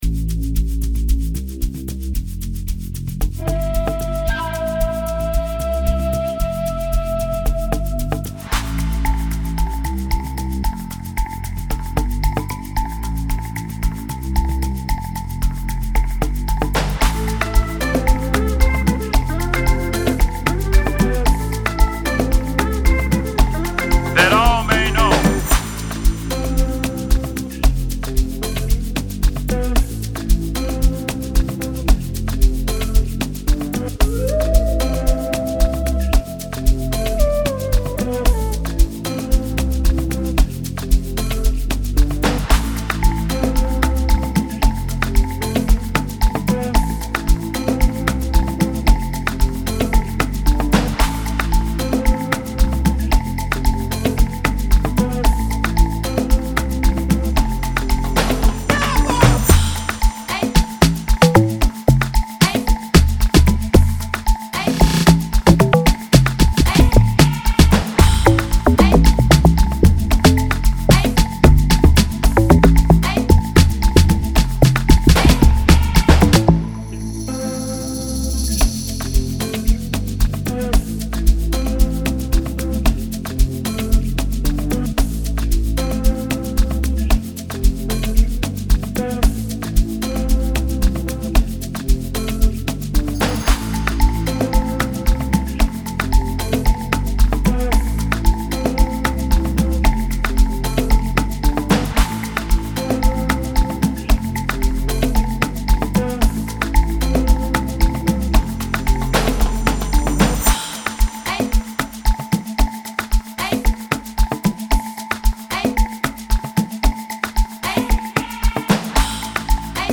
Category: Amapiano